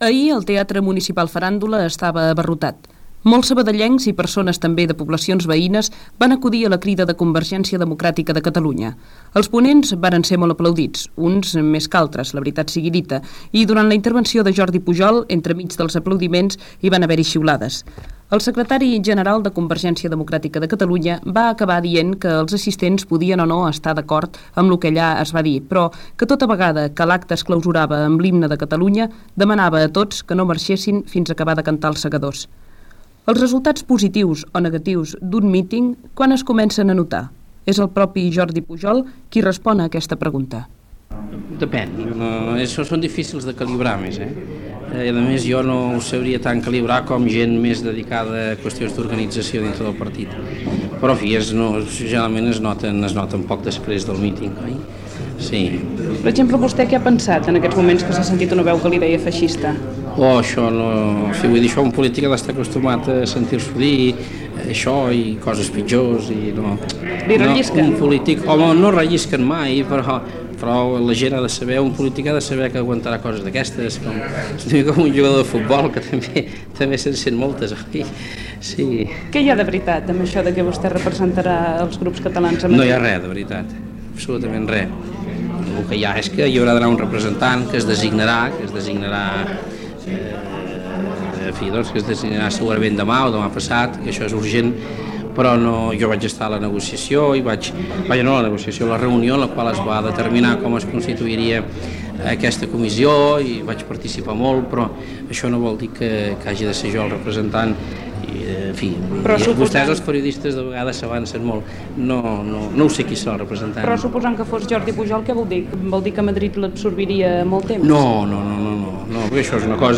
Informació del míting de Convergència Democràtica de Catalunya a Sabadell, amb una entrevista al polític Jordi Pujol
Informatiu